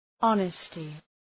{‘ɒnəstı}